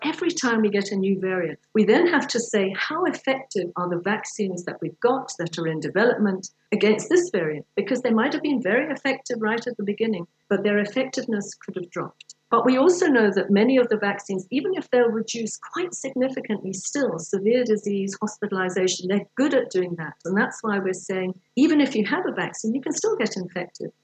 During a parliamentary briefing this week, Rees said getting vaccinated would also help reduce pressure on the country’s healthcare system.